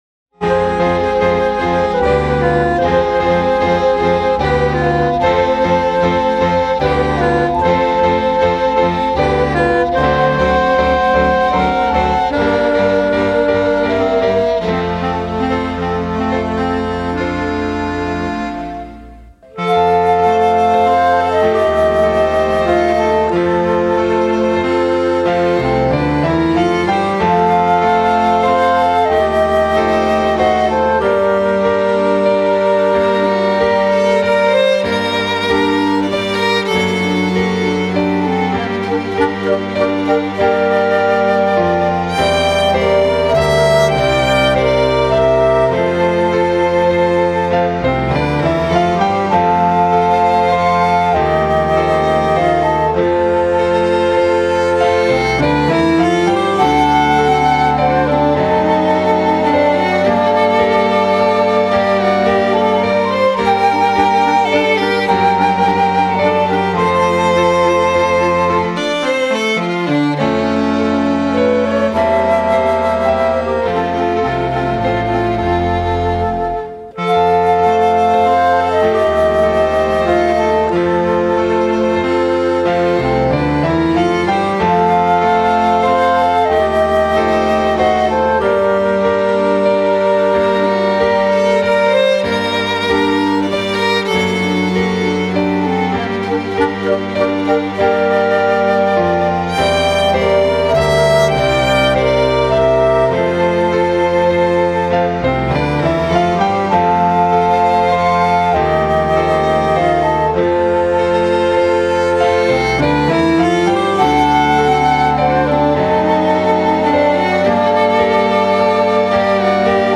817   09:07:00   Faixa:     Valsa